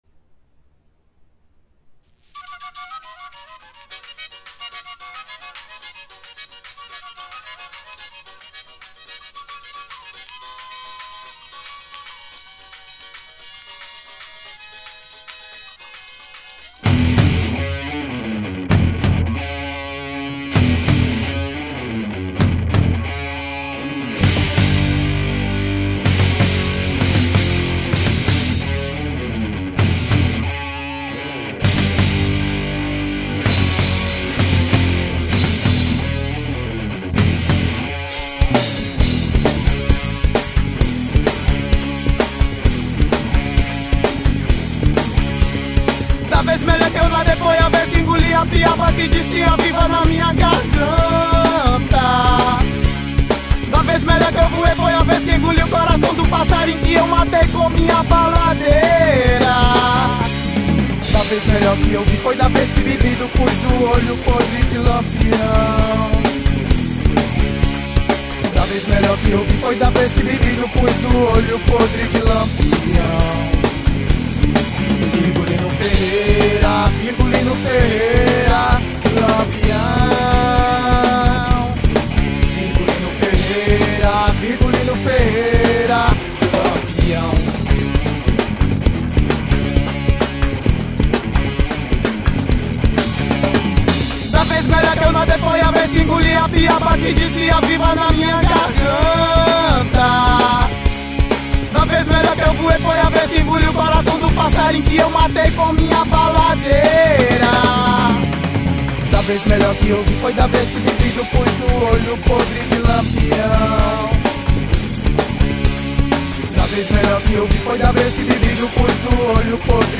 1944   03:47:00   Faixa:     Rock Nacional